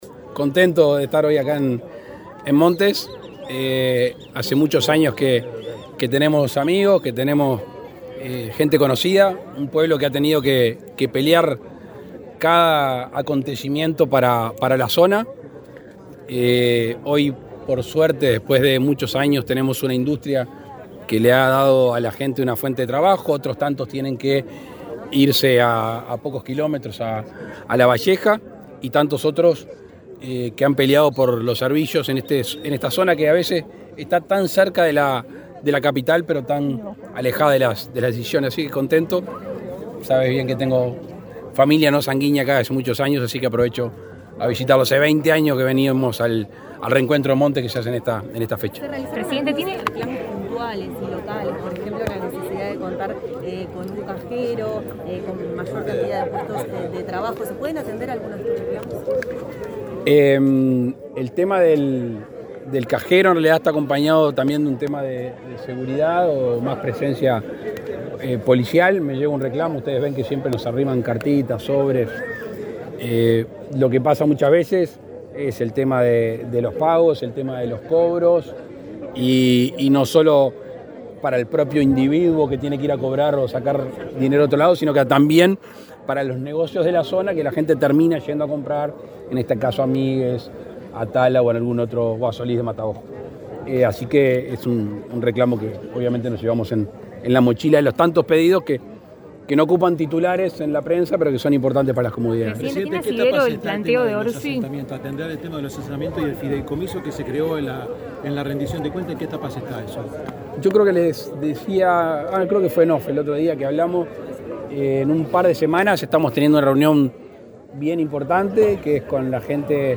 Declaraciones del presidente Luis Lacalle Pou a la prensa
El presidente Luis Lacalle Pou encabezó este viernes 12 la celebración por los 130 años de la localidad de Montes, Canelones, y, luego, dialogó con la